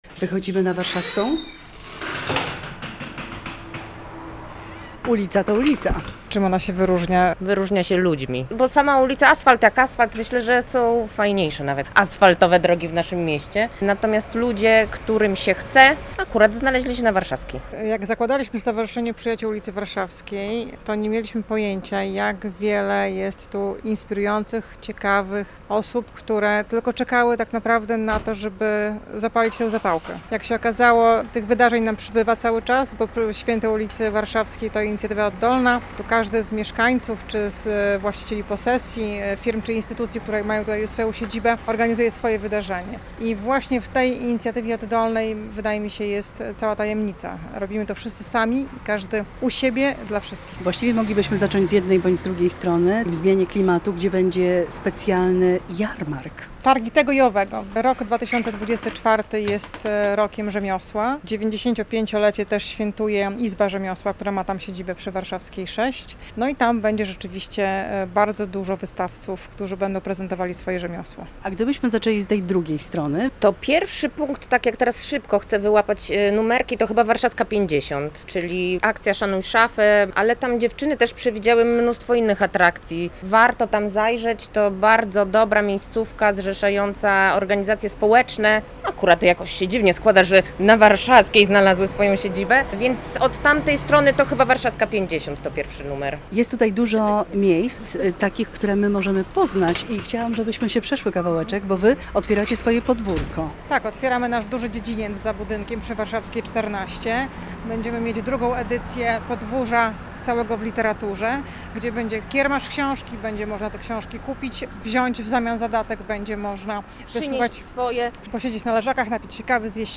Drugie Święto Ulicy Warszawskiej - relacja